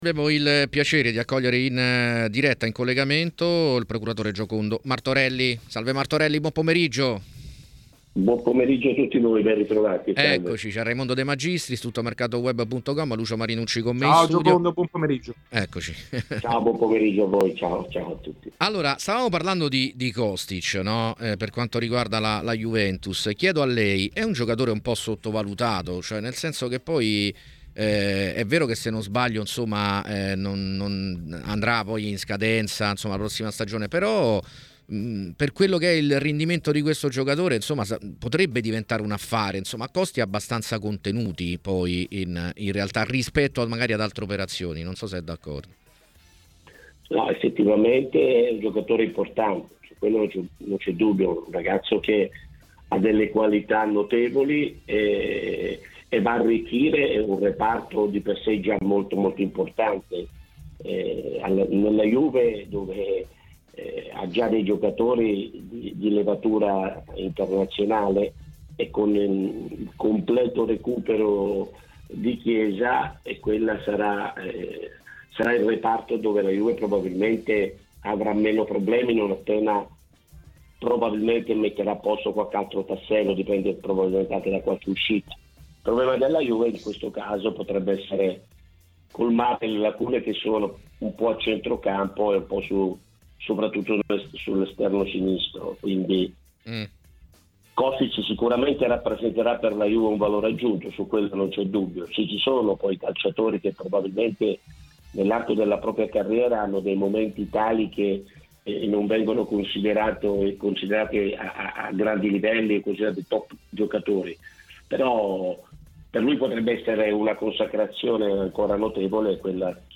Intervenuto su TMW Radio durante la trasmissione A Tutto Mercato